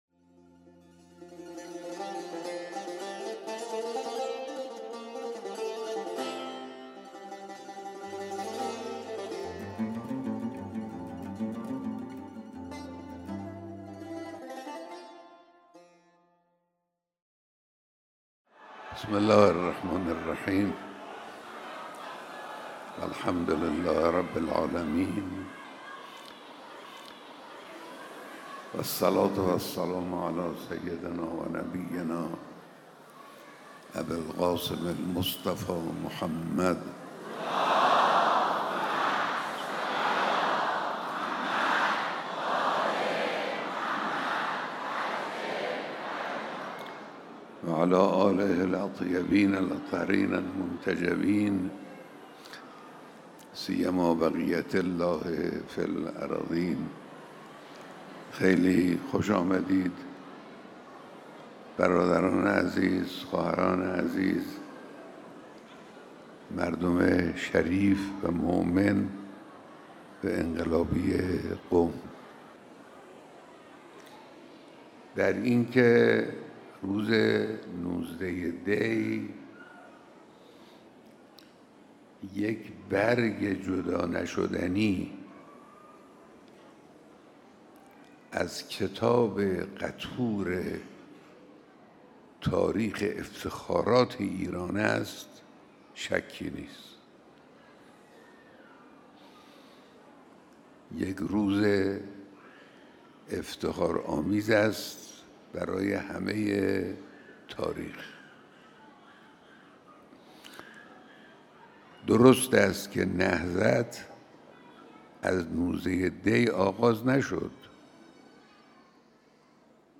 بیانات در دیدار هزاران نفر از مردم قم